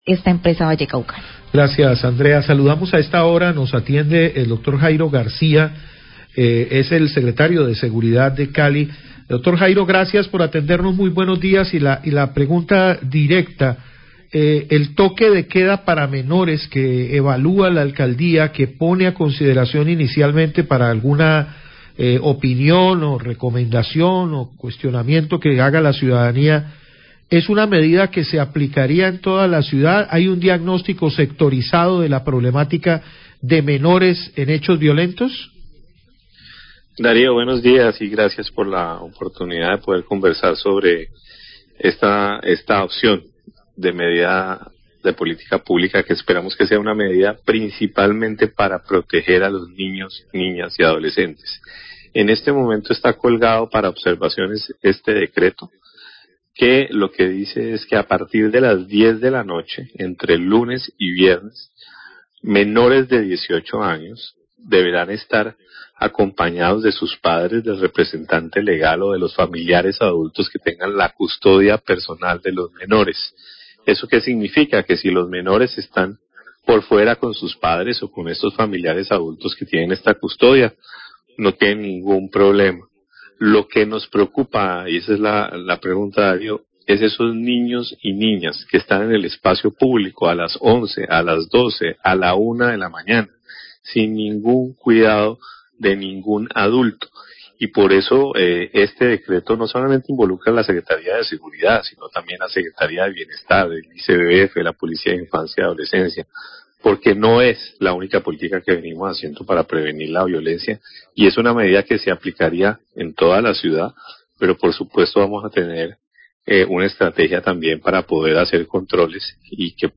Entervista con el Secretario de Seguridad de Cali, Jairo Garcia, quien habla de la presentación de un borrador de decreto de toque de queda para menores de edad en el oriente de Cali, a partir de las 10pm de lunes a viernes. Los menores podrán salir siempre y cuando estén con sus padres o un mayor de edad quien tenga la custodia.